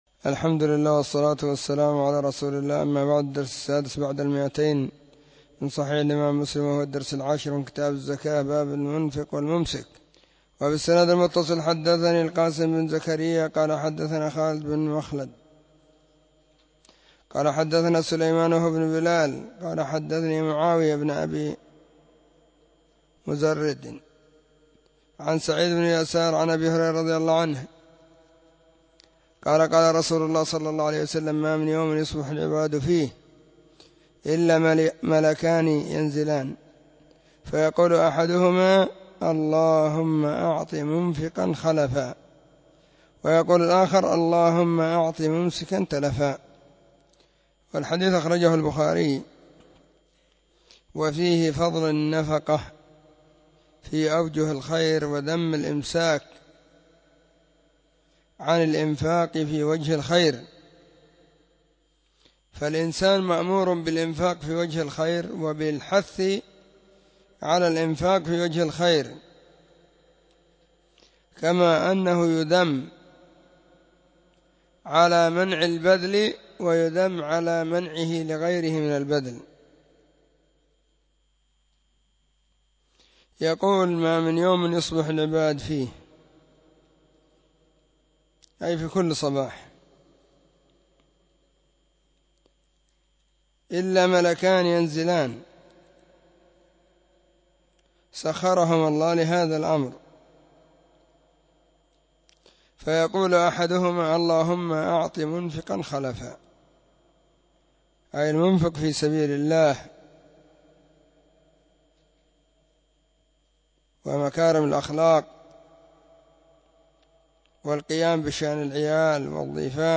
📢 مسجد الصحابة – بالغيضة – المهرة، اليمن حرسها الله.
كتاب-الزكاة-الدرس-10.mp3